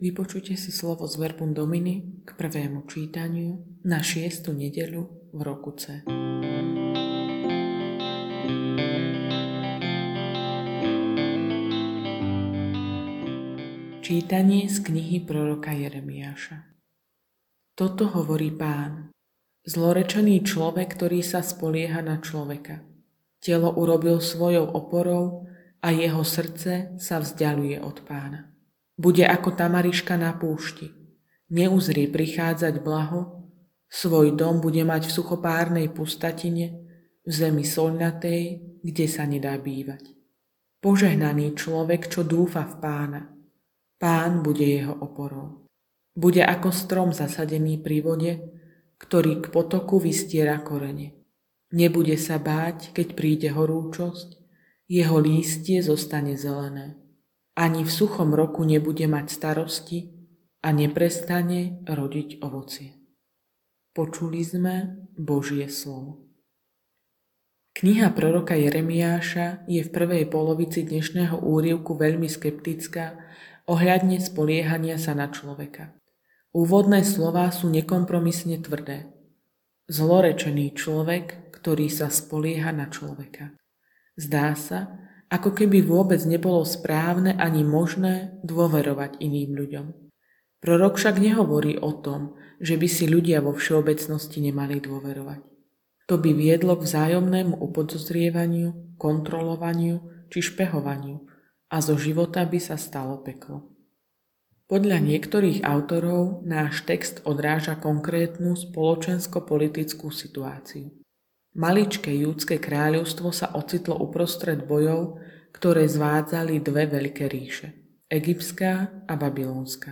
Audionahrávka zamyslenia…